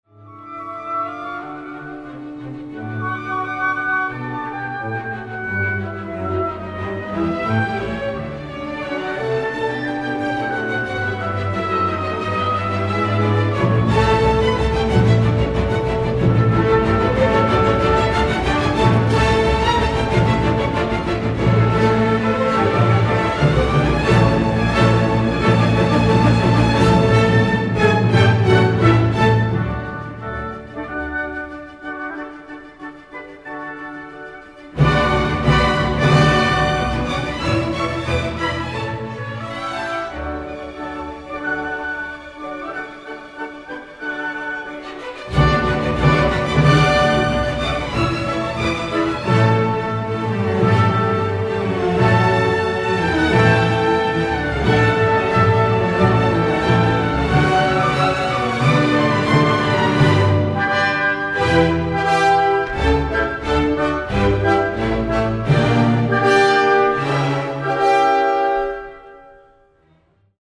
Beethoven quiere ser feliz y no acepta que su sordera sea irremediable; quiere curarse, desea el amor y su corazón desborda de esperanza, sentimientos que parecen ser los que inspiran esta radiante y luminosa sinfonía compuesta en 1802, el mismo año del triste y desconsolado Testamento de Heiligenstadt.